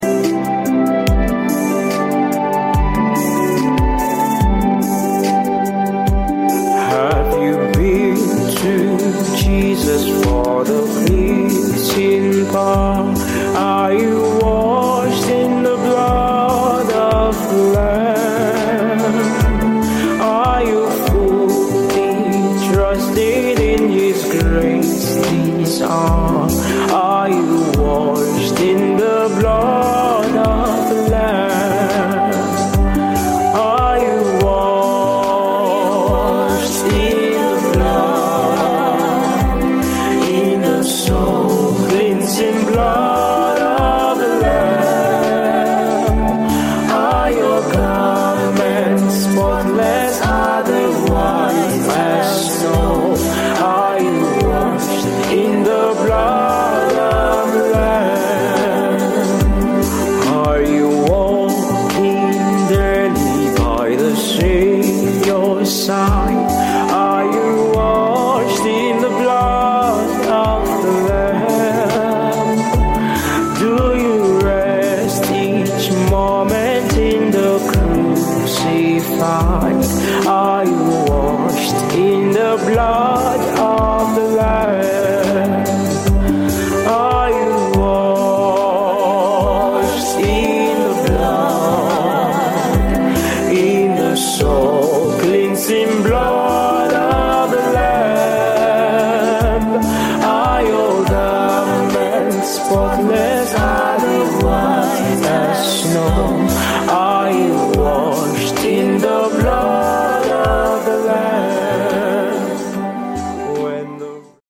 CATHOLIC HYMN